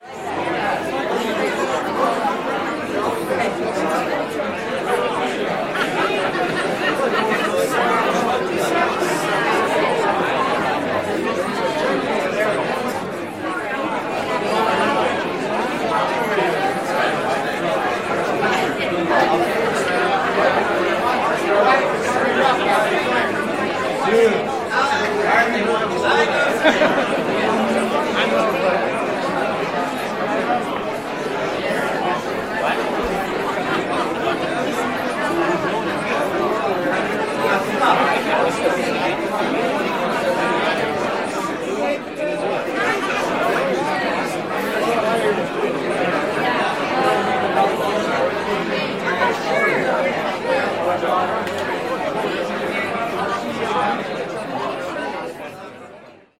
At a large dinner party with friends.